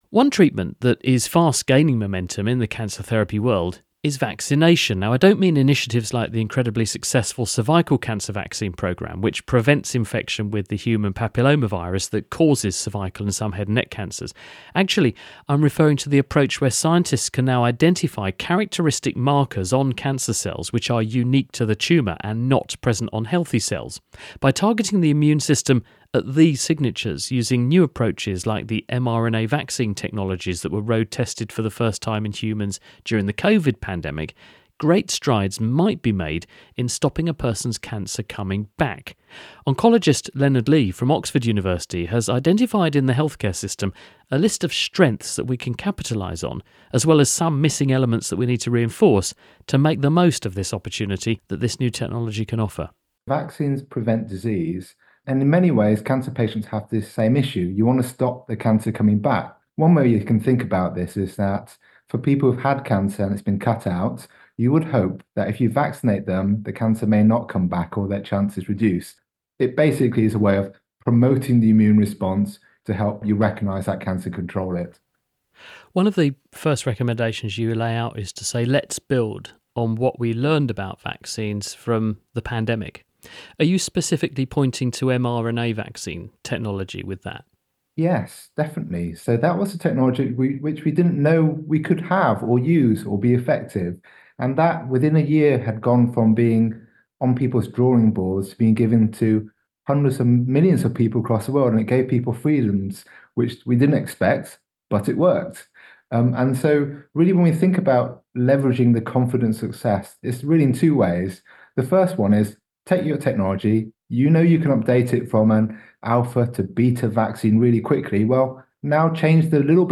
2. Interviews